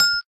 xylophone_4.ogg